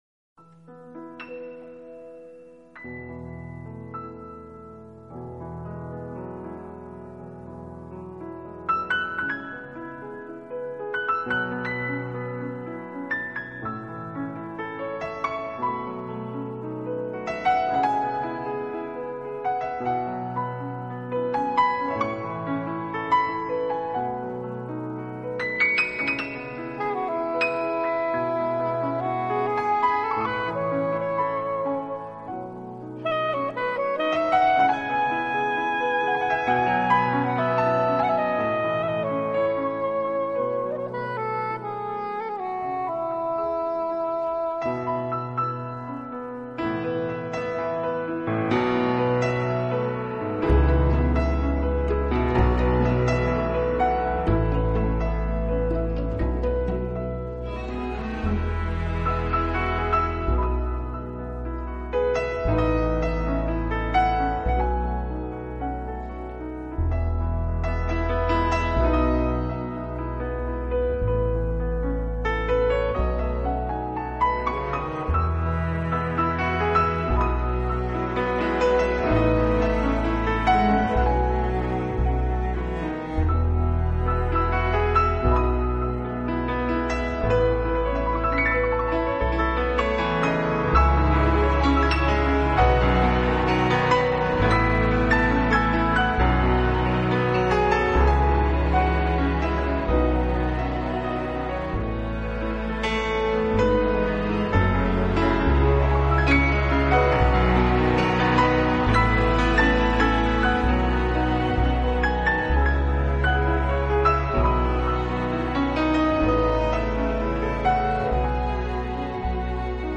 音乐类型：钢琴
加了浪漫的和声与琶音，静静地为我们诉说这个世界曾经发生的爱情悲喜剧。